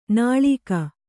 ♪ nāḷīka